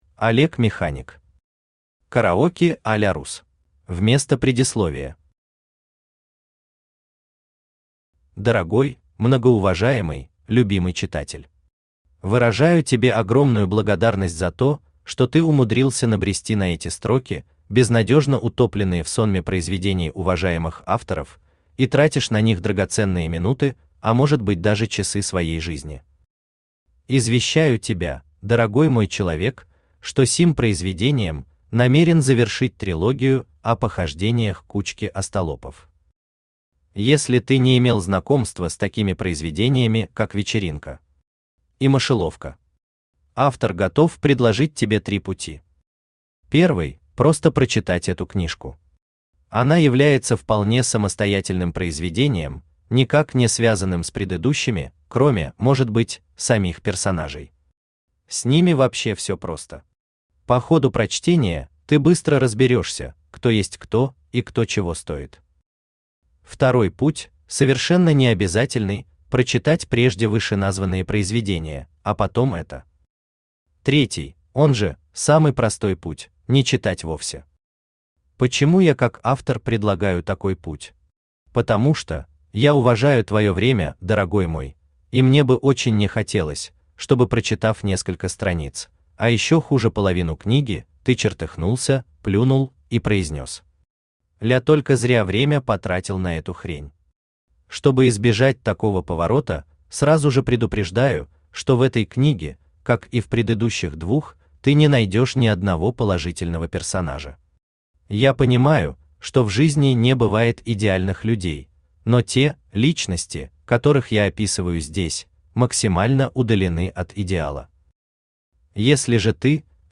Аудиокнига Караоке а-ля русс | Библиотека аудиокниг
Aудиокнига Караоке а-ля русс Автор Олег Механик Читает аудиокнигу Авточтец ЛитРес.